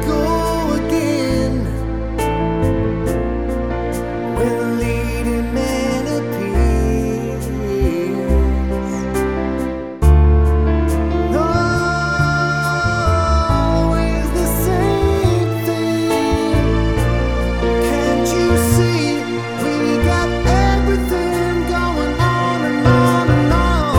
Down 2 Male Key